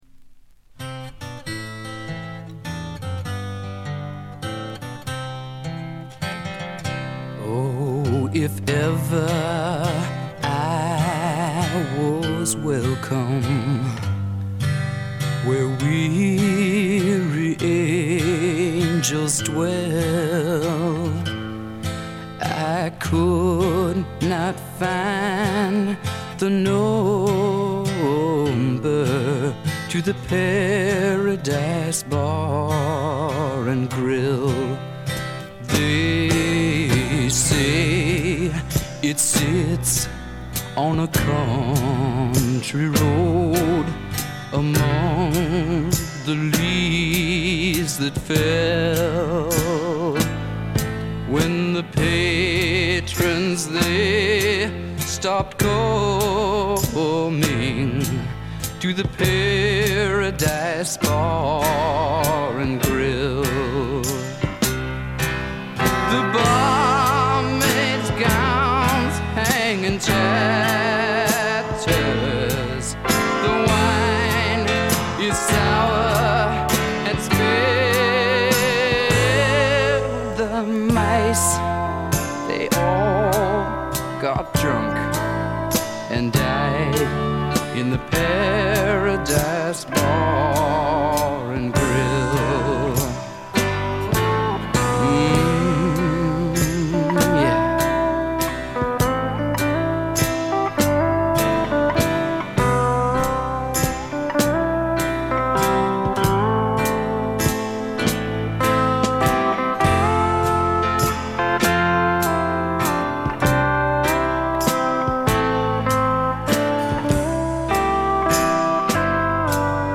ごくわずかなノイズ感のみ。
試聴曲は現品からの取り込み音源です。
Pedal Steel Guitar